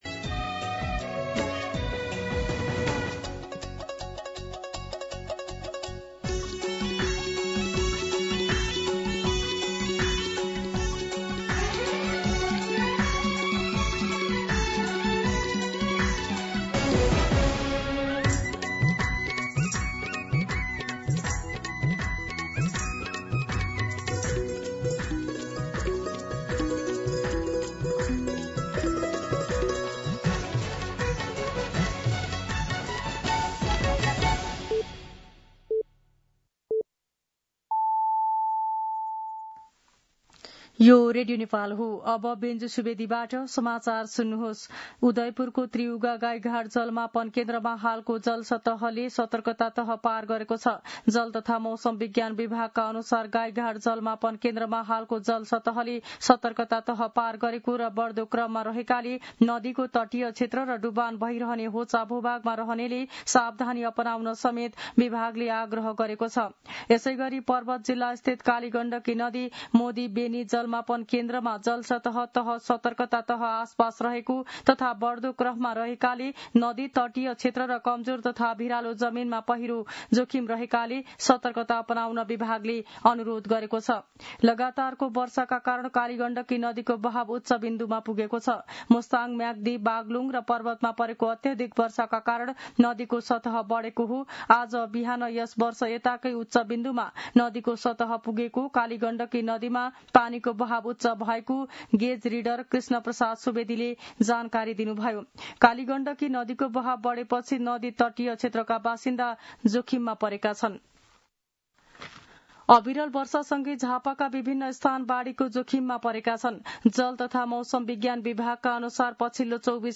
मध्यान्ह १२ बजेको नेपाली समाचार : १८ साउन , २०८२